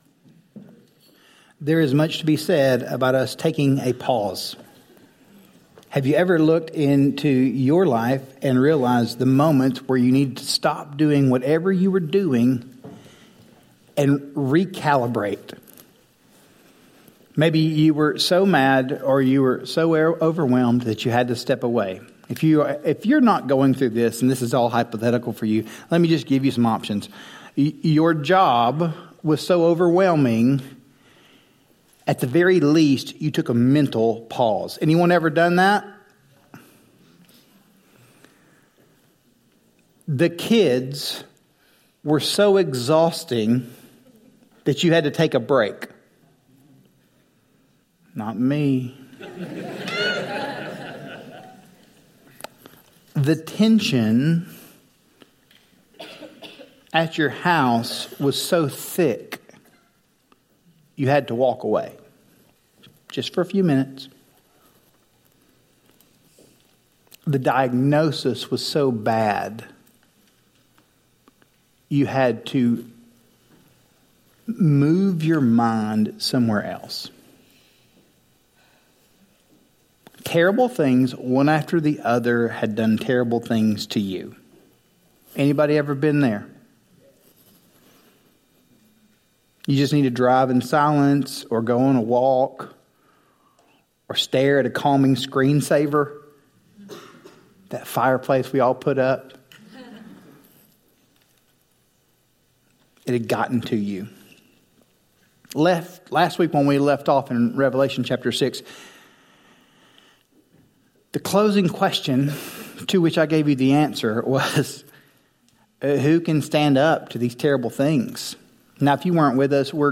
Forward Church Sermons Podcasts